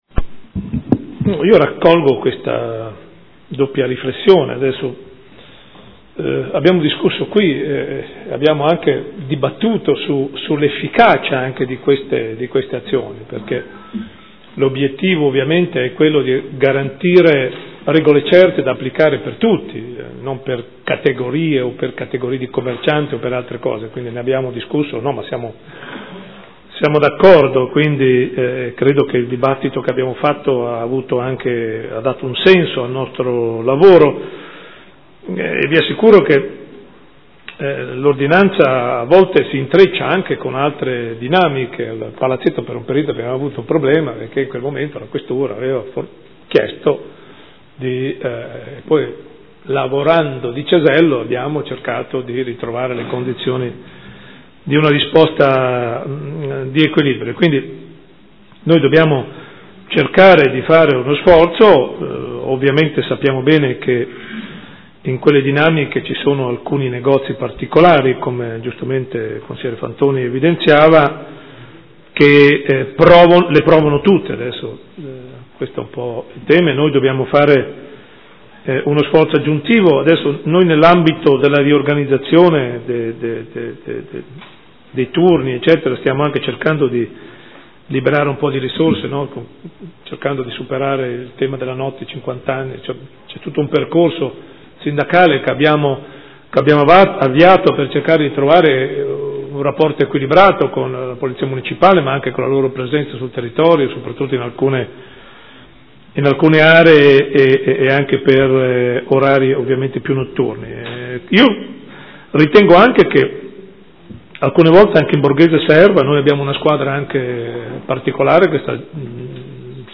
Audio Consiglio Comunale